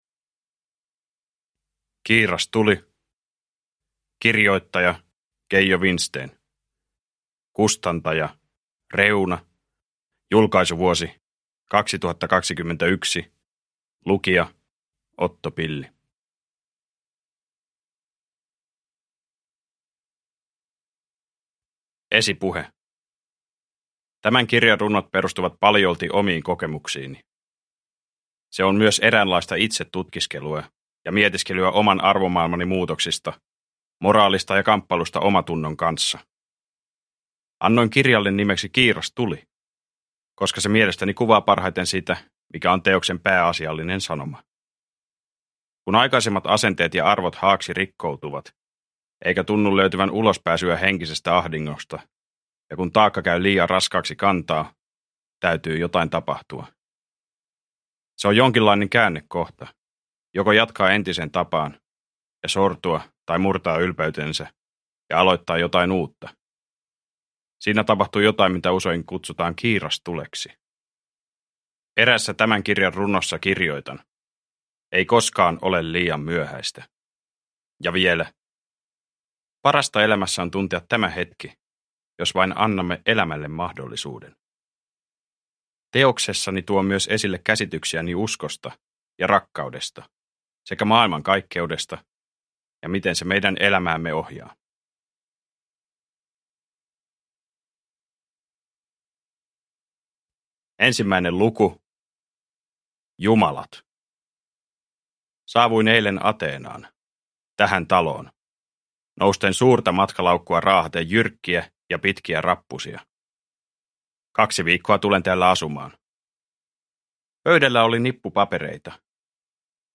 Kiirastuli – Ljudbok – Laddas ner